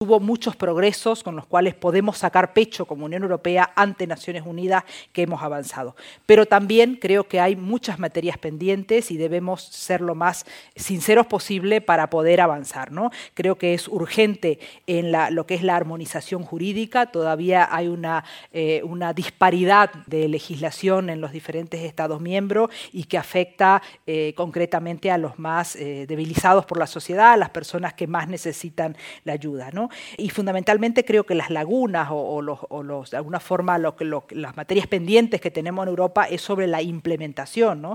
En el mismo ha intervenido la eurodiputada por Madrid, Mónica Silvana, quien ha destacado la importancia de este proyecto ‘Europa se acerca a ti’ “para dar cuenta de nuestro trabajo y recoger las necesidades de la ciudadanía con discapacidad que deben ser tenidas en cuenta en los distintos fondos estructurales de la Unión Europea”.